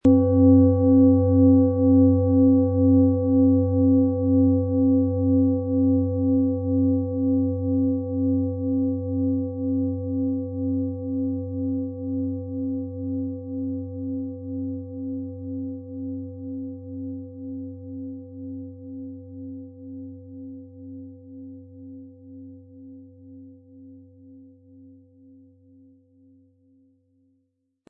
Nach uralter Tradition von Hand getriebene Planetenklangschale Biorhythmus Geist. Weitergegebenes Wissen in Familien, die seit Jahrhunderten Klangschalen herstellen, machen diese Klangschalen so einmalig.
• Mittlerer Ton: DNA
• Höchster Ton: Delfin
Unter dem Artikel-Bild finden Sie den Original-Klang dieser Schale im Audio-Player - Jetzt reinhören.
MaterialBronze